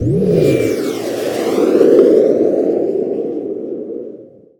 combat / aircraft / turn.wav
turn.wav